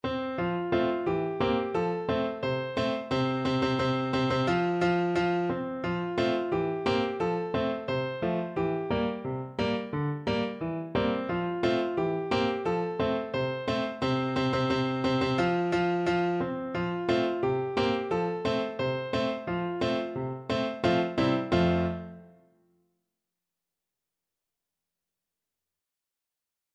Piano version
Traditional Piano
Time Signature: 2/4 ( View more 2/4 Music ) Tempo Marking: Steady march =c.88 Score Key: F major (Soun